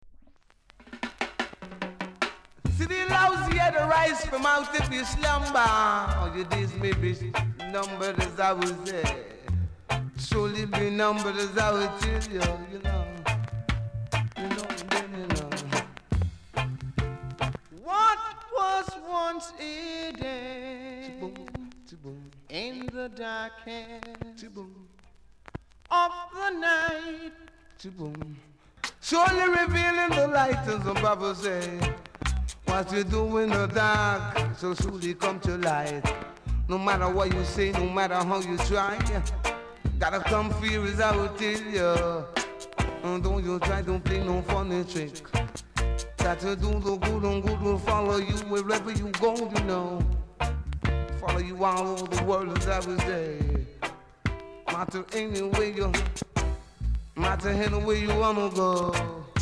コメント DEEP ROOTS!!RARE!!※裏面に目立つ傷ありノイズ大きめです。